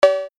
Sound Buttons: Sound Buttons View : Cowbell